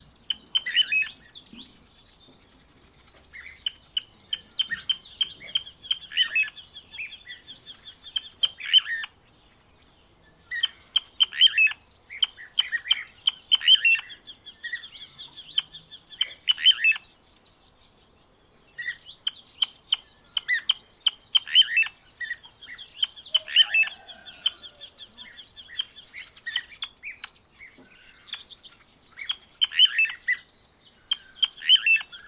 kleine plevier
🔭 Wetenschappelijk: Charadrius dubius
♪ contactroep
kleine plevier_roep.mp3